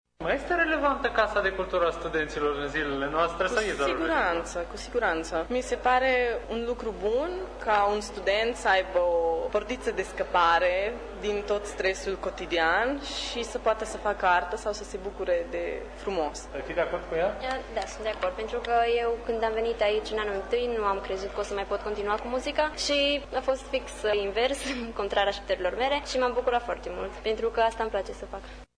Studenții mureșeni sunt de părere că instituția mai are încă relevanță în viața lor: